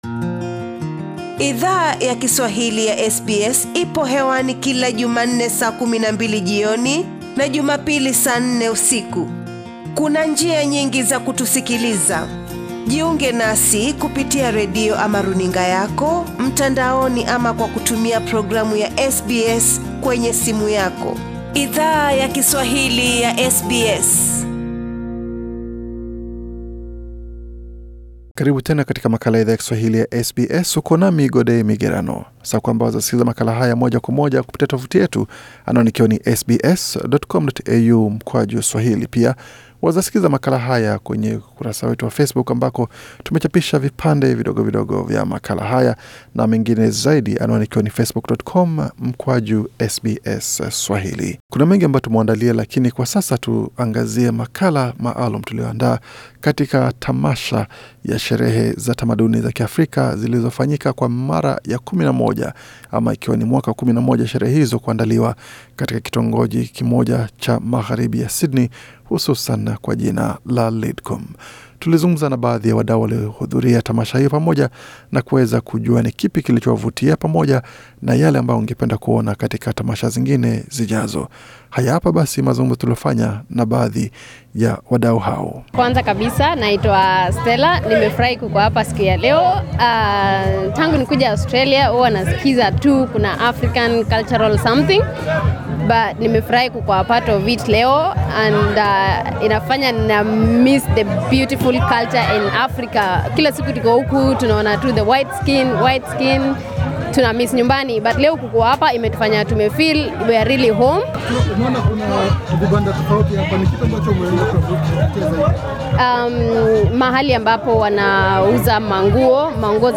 SBS Swahili attend the 11th edition of Africultures festival, at Wyatt Park, Lidcombe, NSW where we spoke with members of the Swahili speaking communities about their experiences at the festival.